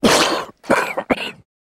male_cough3.ogg